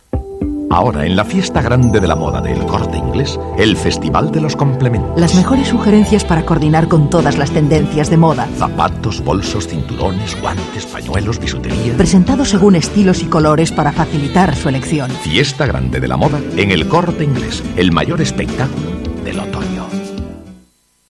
Anuncios sobre la fiesta de la moda, servicios de El Corte Inglés, supermercados, ropa de baño... narrados con voces sugerentes y aún en pesetas, que nos recuerdan cómo la herencia del pasado puede servirnos para alumbrar nuevas estrategias en el futuro.
Anuncio de complementos El Corte Inglés